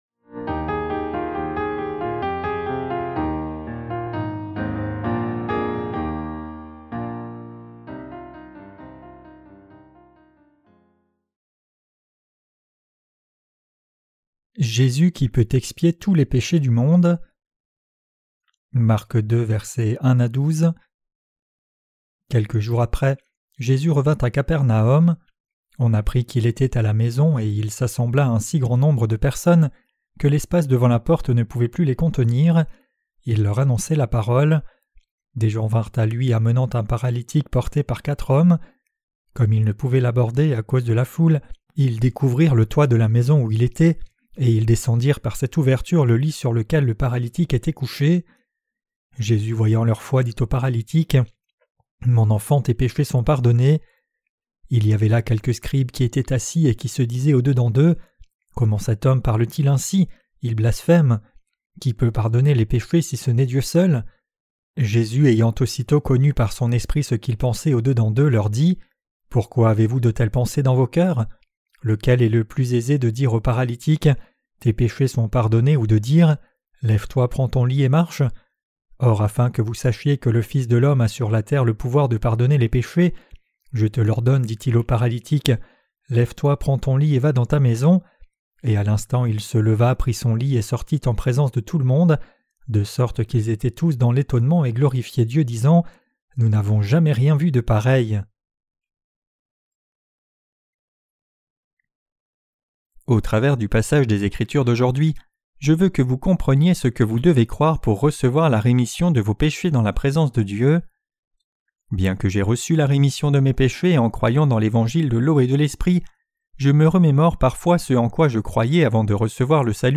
Sermons sur l’Evangile de Marc (Ⅰ) - QUE DEVRIONS-NOUS NOUS EFFORCER DE CROIRE ET PRÊCHER? 4.